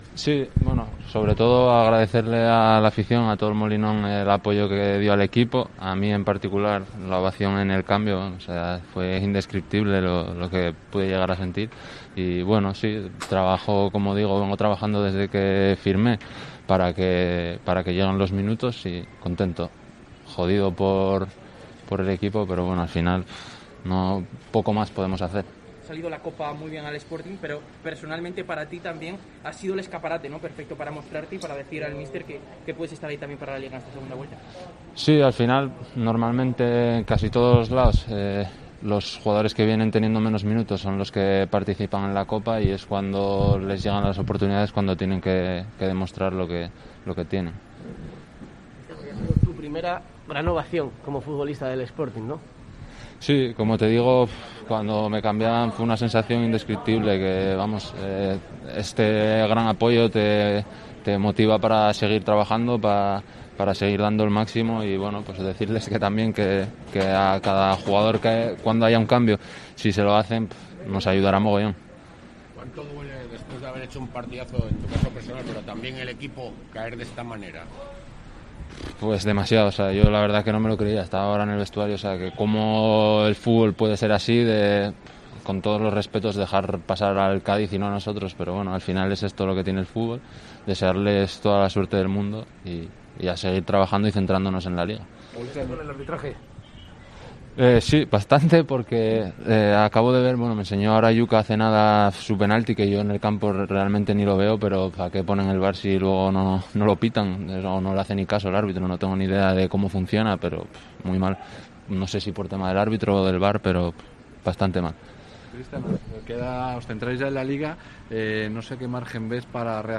en zona mixta (Sporting-Cádiz)